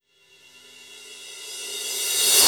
Track 14 - Reverse Cymbal OS.wav